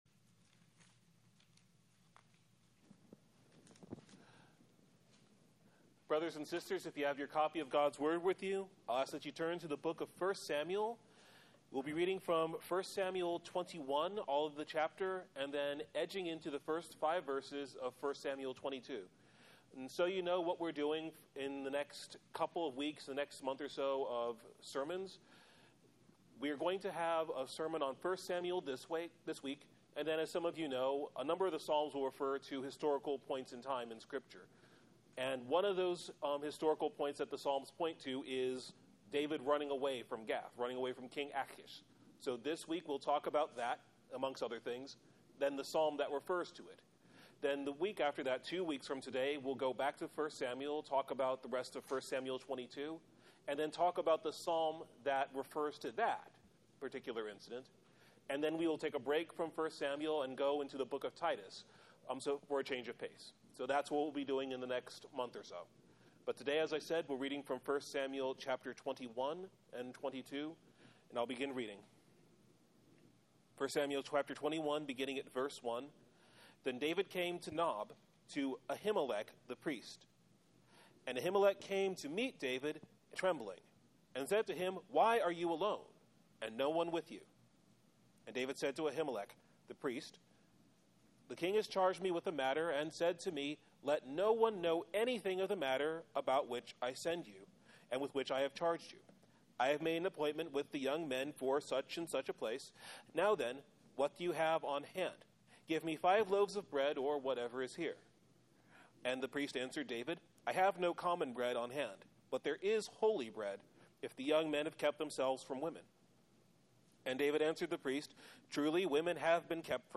Sermon Text: 1 Samuel 21:1-22:5